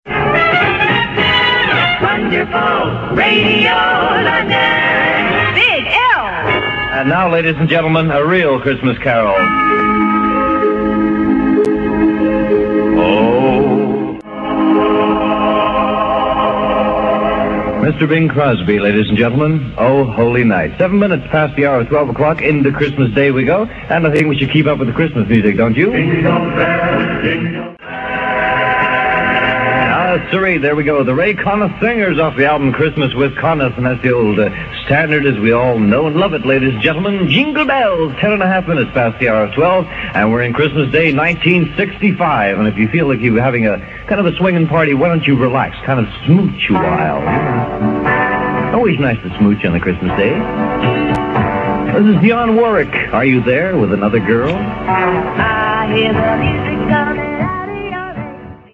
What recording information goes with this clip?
Put your silly hat on now and remember the way it was on-air over Christmas 1965.